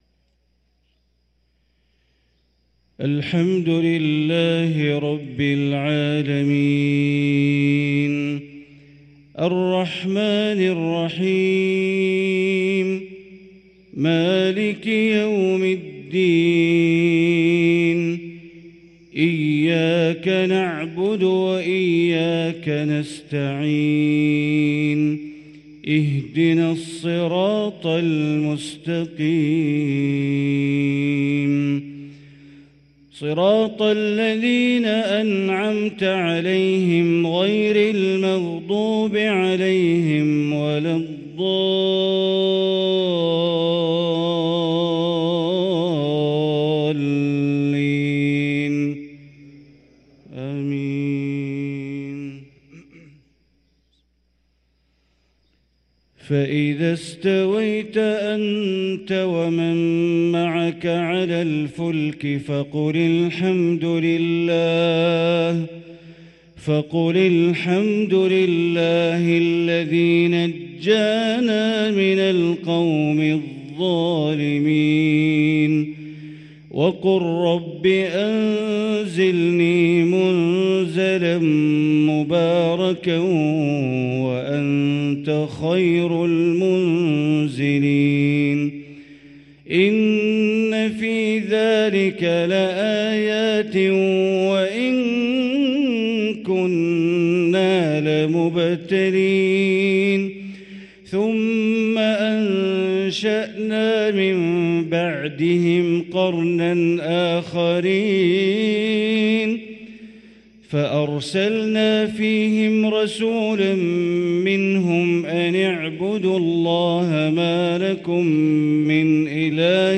صلاة الفجر للقارئ بندر بليلة 14 شعبان 1444 هـ
تِلَاوَات الْحَرَمَيْن .